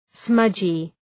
{‘smʌdʒı}